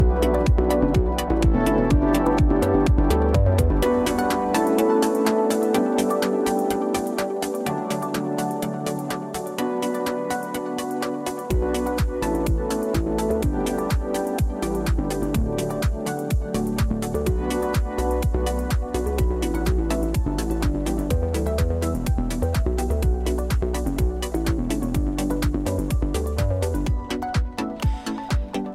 Musical AI – Musica ed intelligenza artificiale